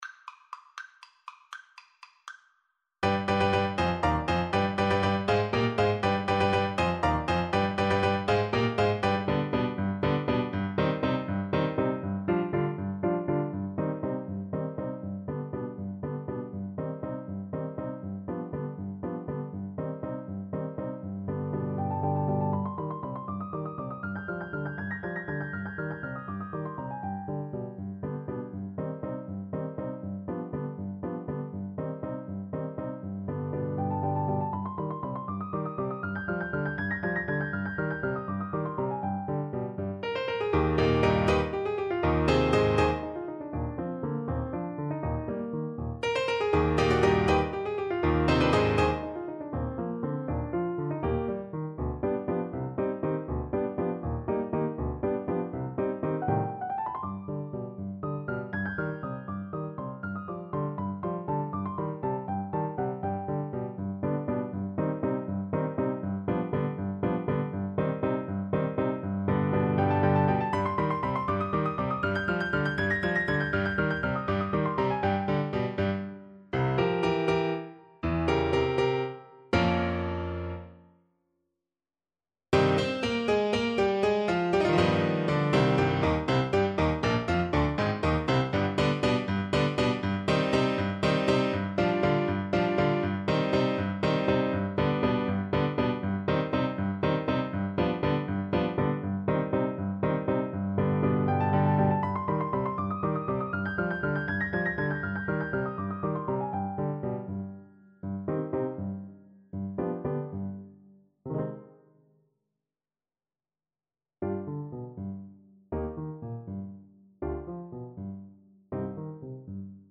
3/8 (View more 3/8 Music)
Allegro vivo (.=80) (View more music marked Allegro)